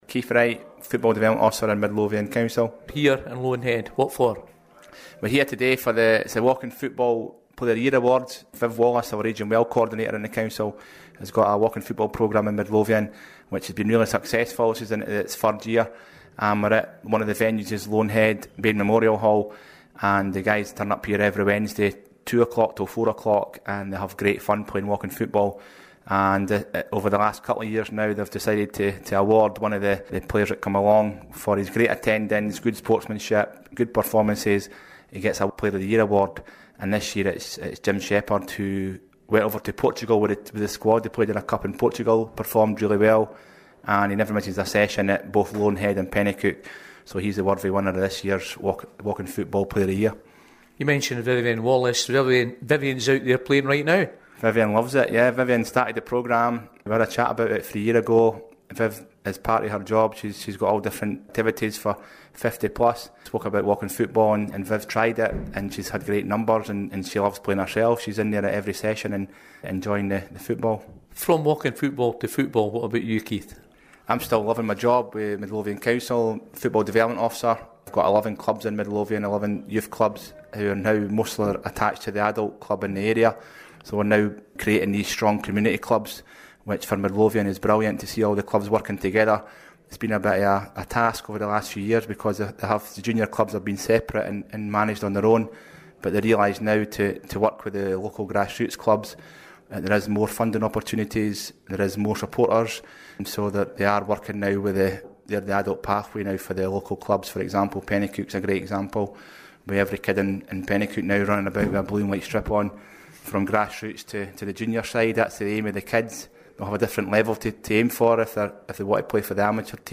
At the Bayne Memorial Hall in Loanhead at the Player of the Year Award